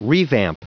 Prononciation du mot revamp en anglais (fichier audio)
Prononciation du mot : revamp